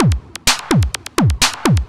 DS 127-BPM B4.wav